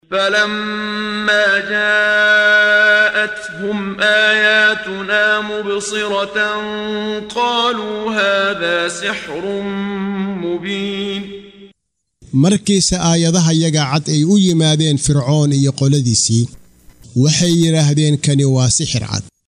Waa Akhrin Codeed Af Soomaali ah ee Macaanida Suuradda An-Namal ( Quraanjada ) oo u kala Qaybsan Aayado ahaan ayna la Socoto Akhrinta Qaariga Sheekh Muxammad Siddiiq Al-Manshaawi.